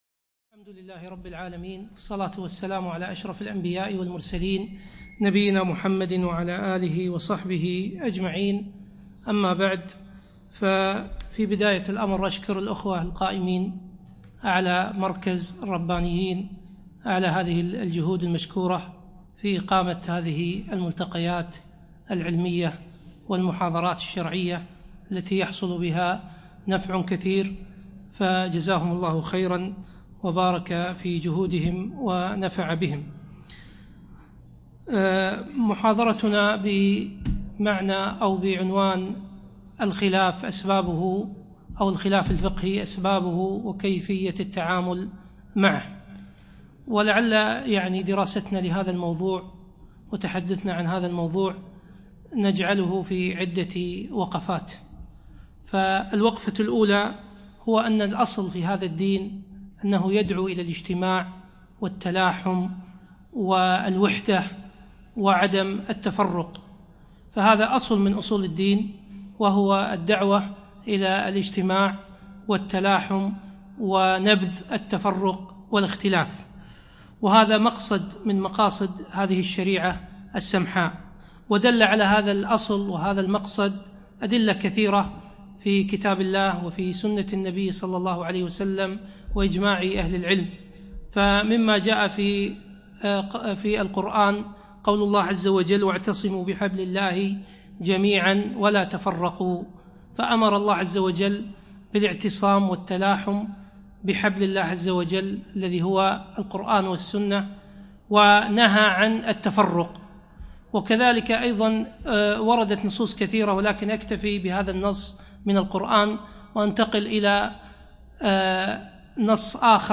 يوم الإربعاء 9 جمادى ثاني 1438 الموافق 8 3 2017 في مسجد مضحي الكليب العارضية